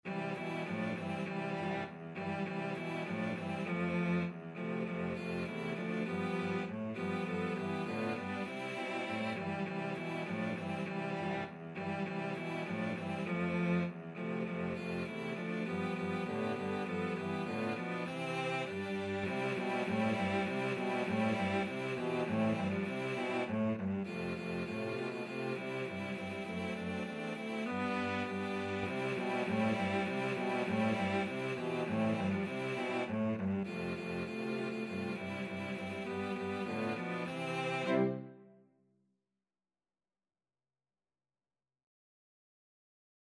Free Sheet music for Cello Quartet
4/4 (View more 4/4 Music)
C major (Sounding Pitch) (View more C major Music for Cello Quartet )
Cello Quartet  (View more Easy Cello Quartet Music)
Traditional (View more Traditional Cello Quartet Music)